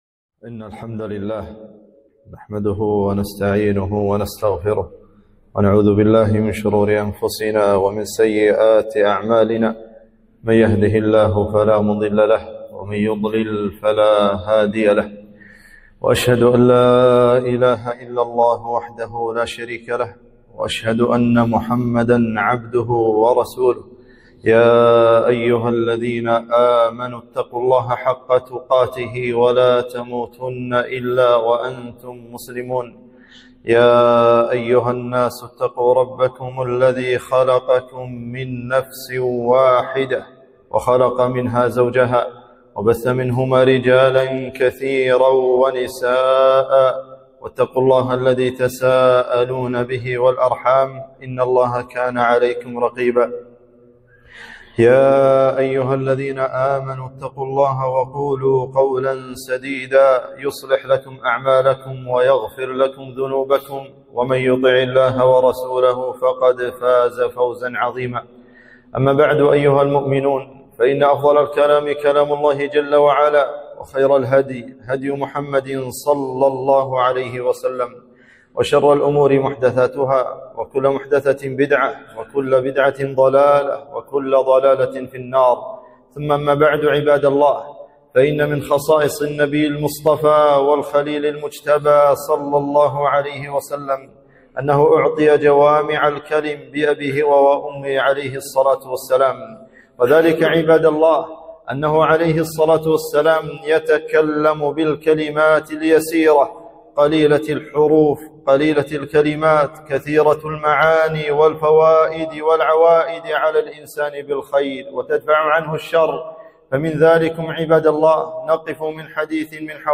خطبة - سبيل النجاة